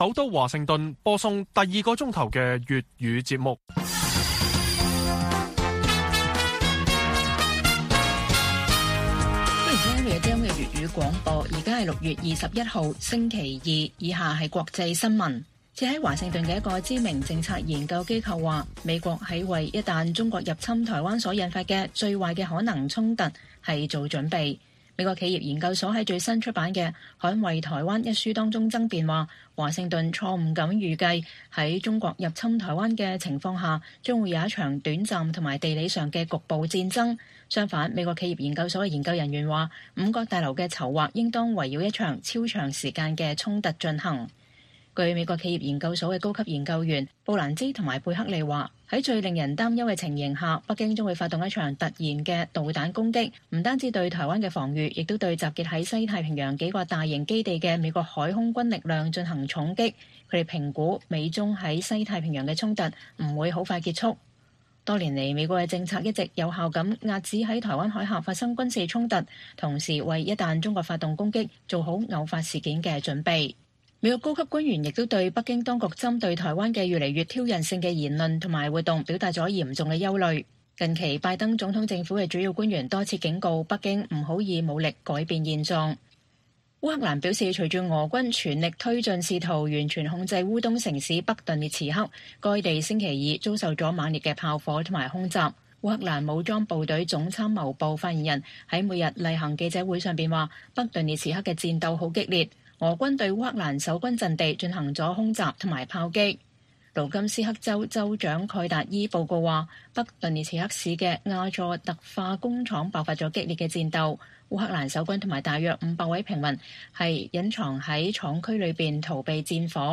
粵語新聞 晚上10-11點 : 日本和德國加強軍力，有人歡樂有人愁